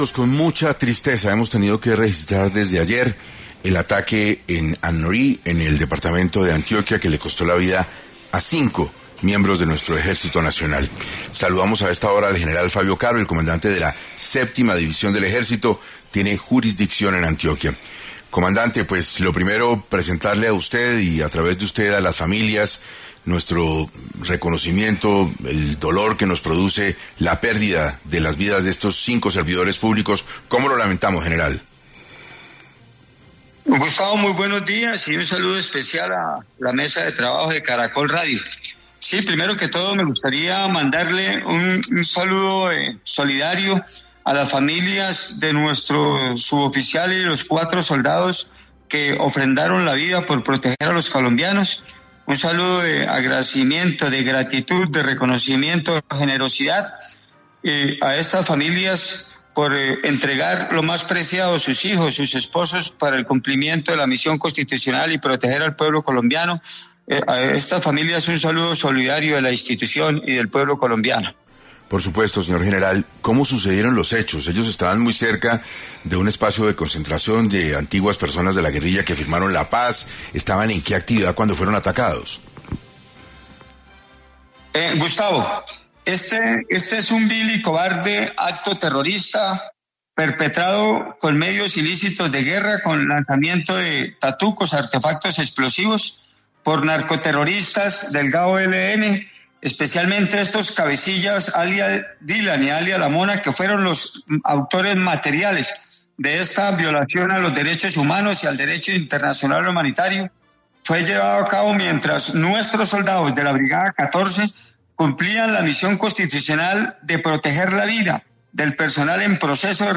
En Caracol Radio estuvo el general Fabio Caro, comandante de la Séptima División del Ejército